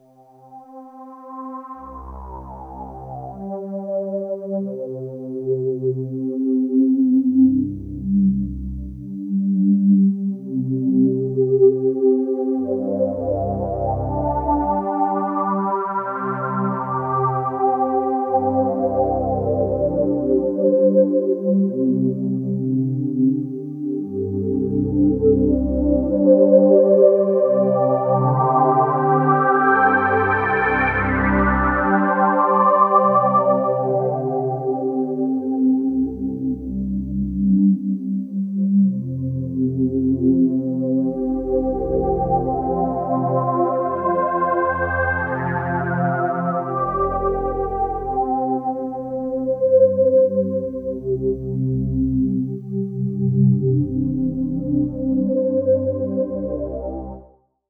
Speaking of which...here's something using the Rotor and the 4 oscs all set to different tunings:
Solaris Supersaw with Rotor
(I used a basic delay module in Scope for examples #3, #4 & #5. They are both using the lowpass 24dB filter as well.)
SolSupersawRotor.wav